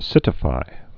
(sĭtĭ-fī)